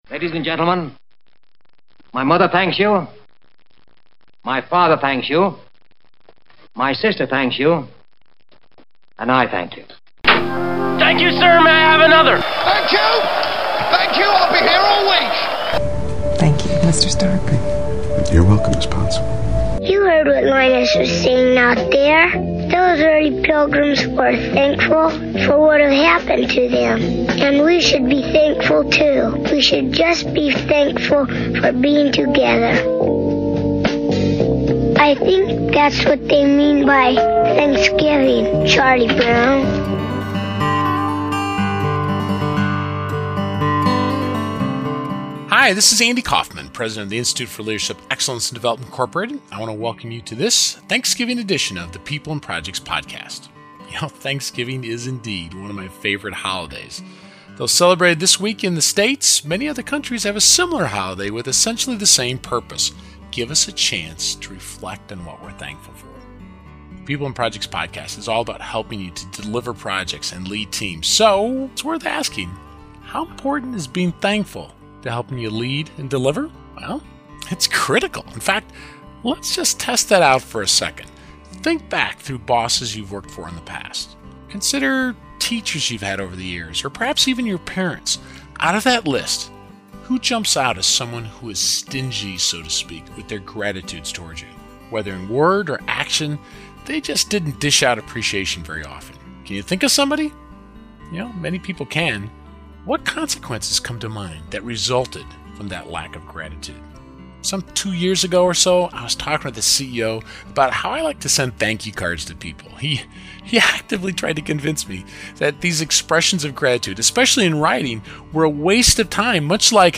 As many of you know, this year we opened it up for people to call in with their message so we could include their thoughts in this podcast .